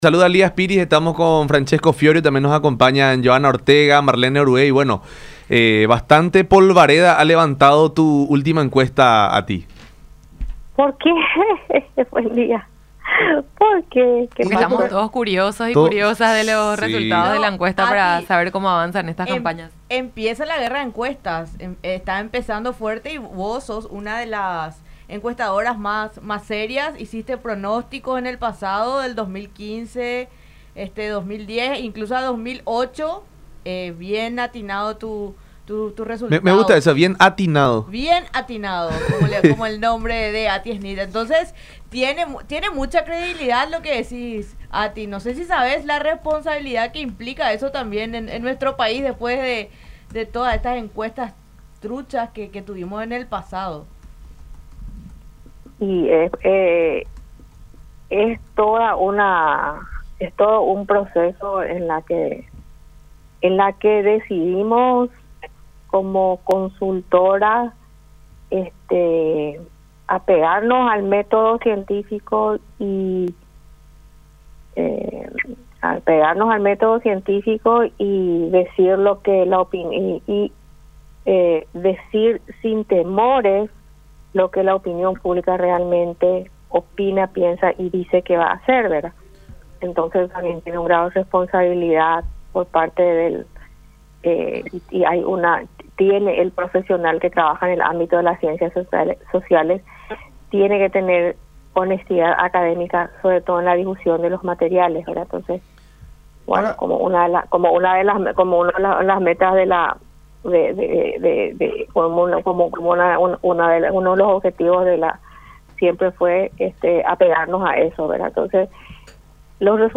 en diálogo con La Unión Hace La Fuerza por Unión TV